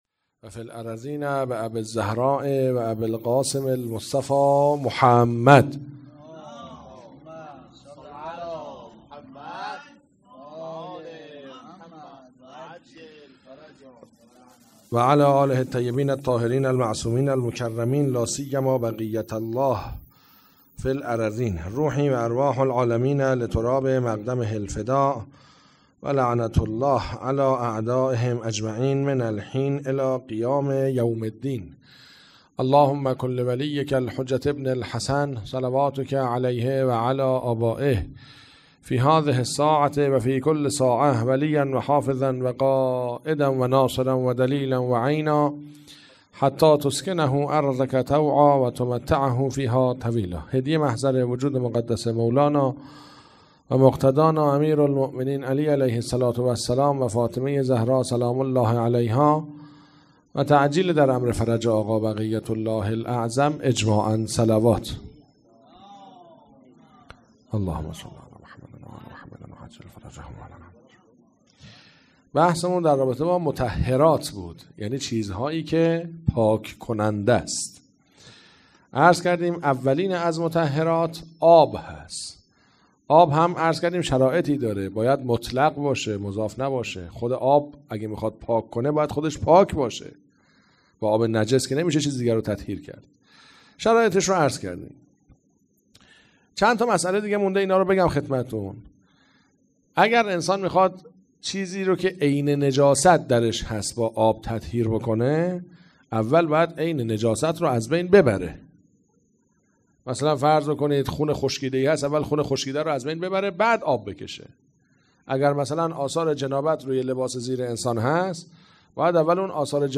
هیئت عقیله بنی هاشم سبزوار
سلسله جلسات احکام